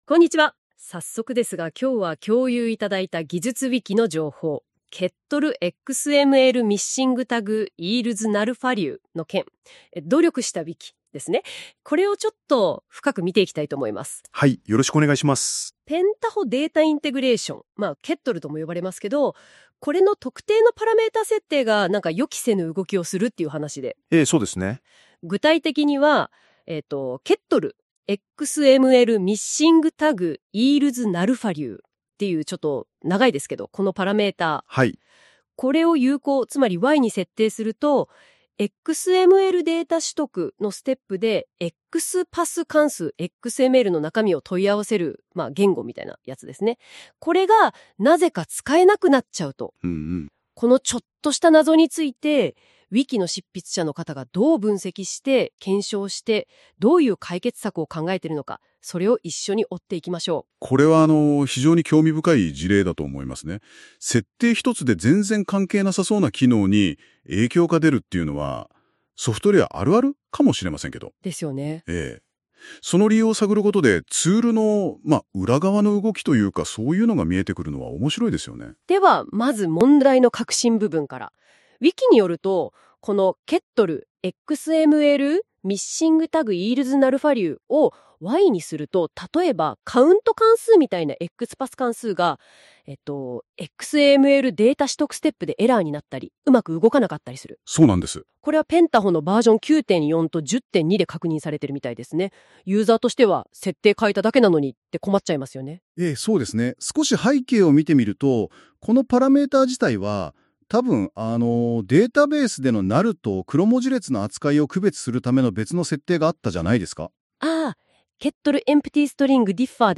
音声解説
Google NotebookLMで音声解説を作ってもらいました。